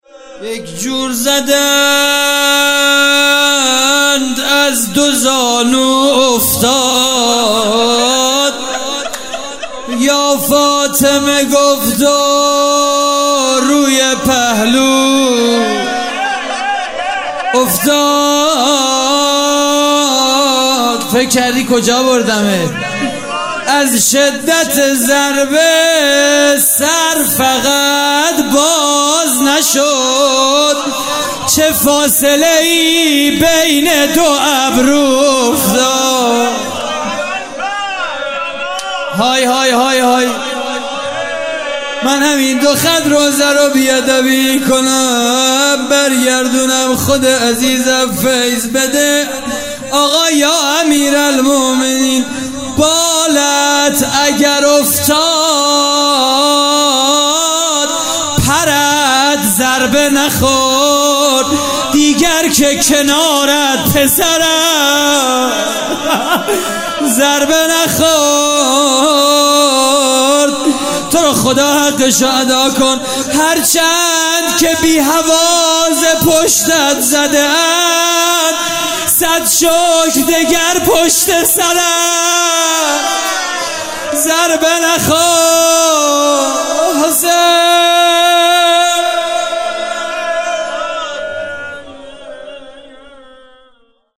روضه: یک جور زدند
مراسم عزاداری شهادت حضرت امیر (ع) (21 رمضان)